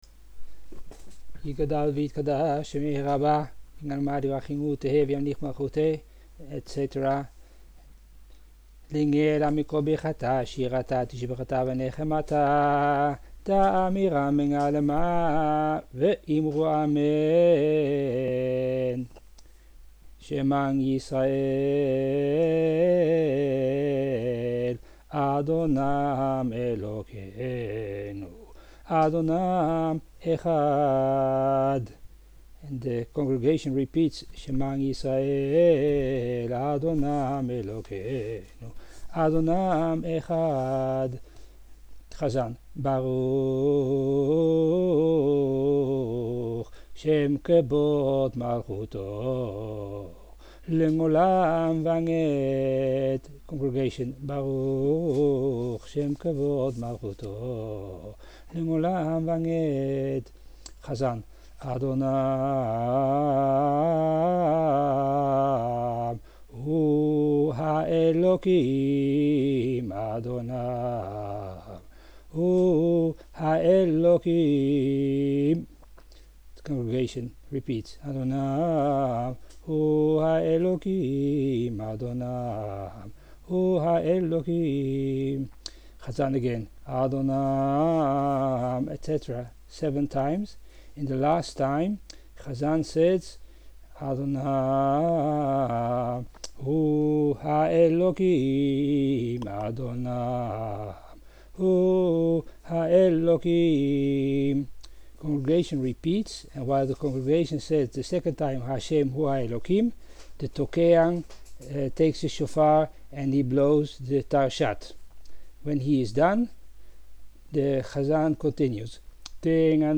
end of ne'ila and the shofar blowing (first part of recording, second part is for hoshangana rabba).